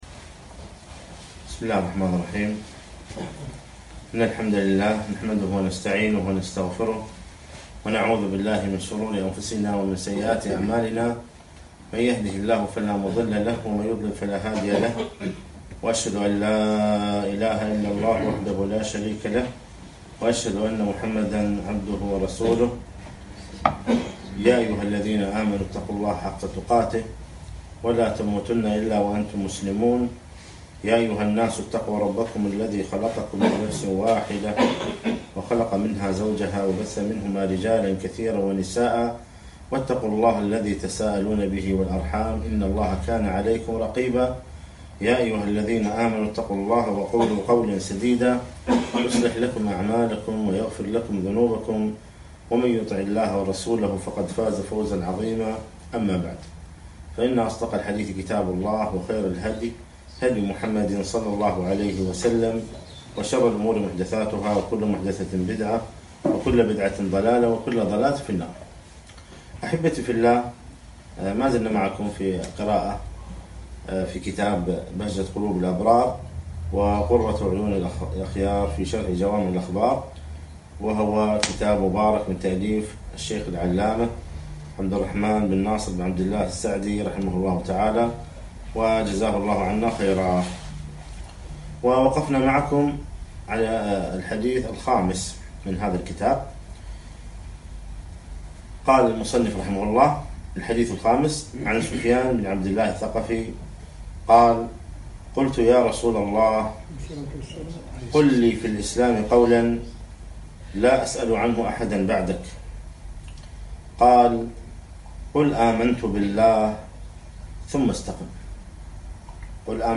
قل آمنت بالله ثم استقم - محاضرة